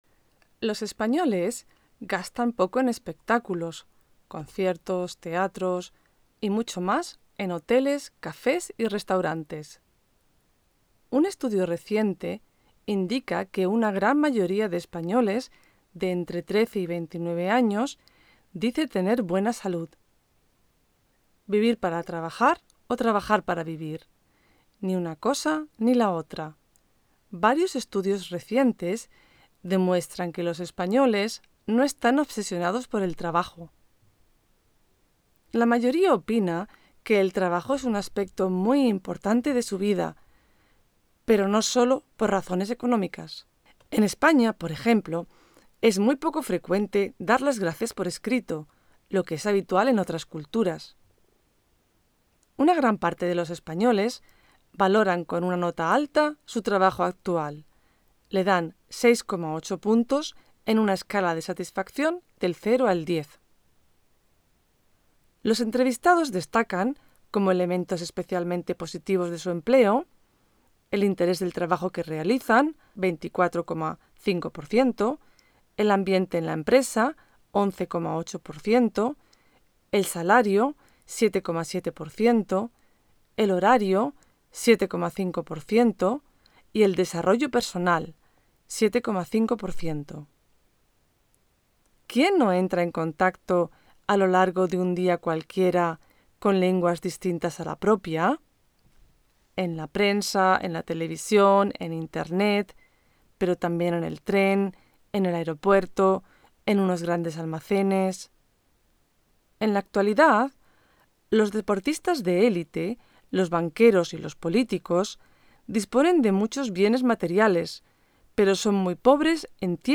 EJERCICIOS GENERALES